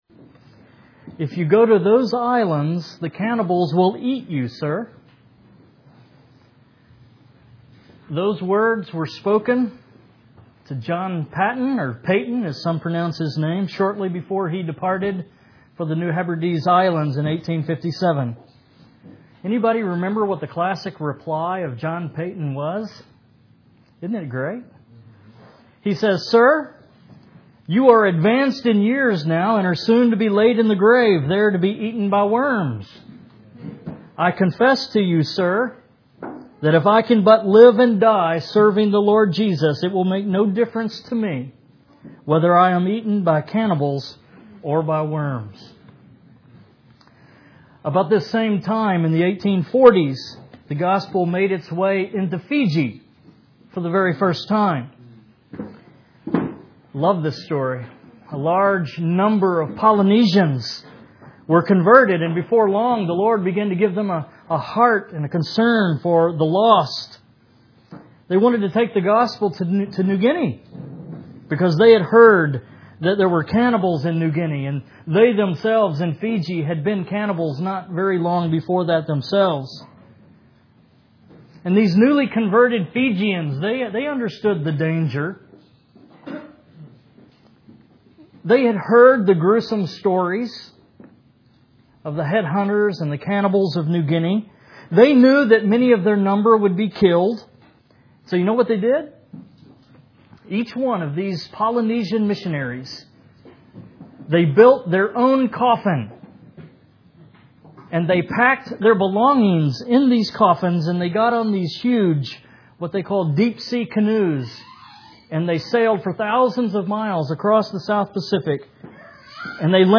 Power Encounter in Papua New Guinea (Part 2)